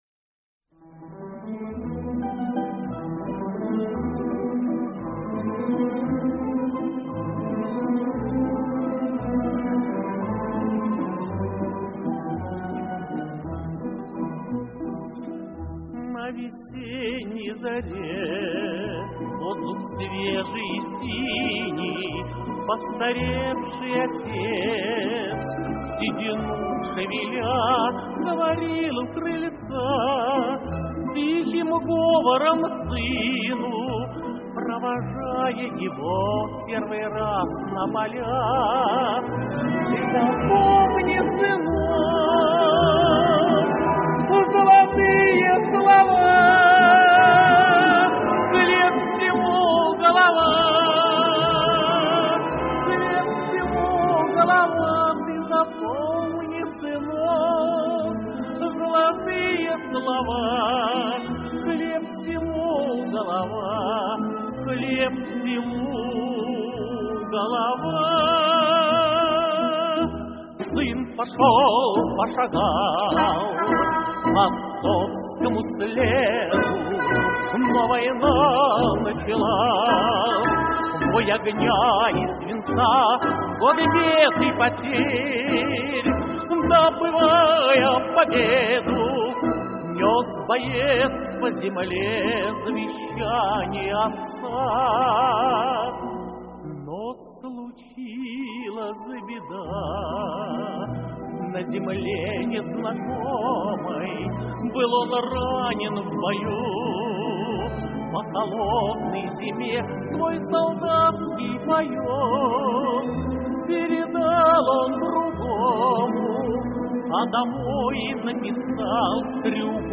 Трепетное, душевное исполнение песни...